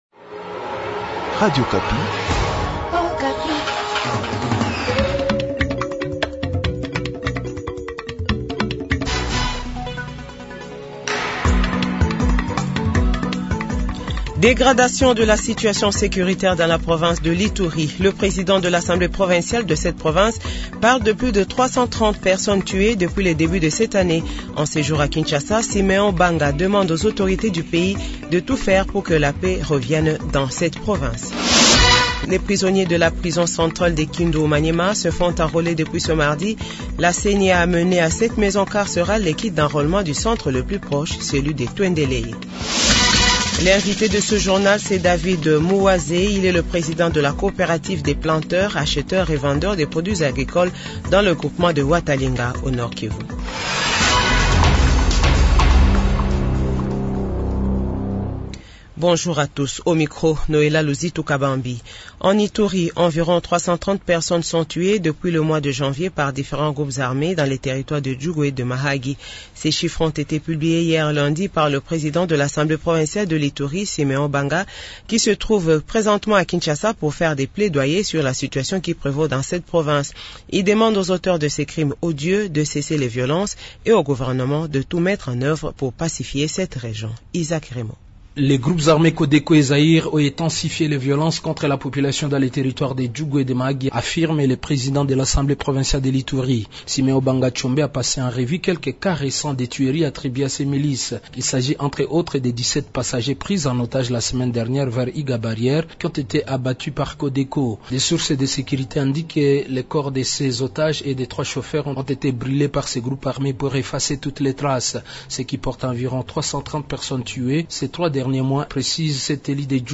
Journal Francais 15h00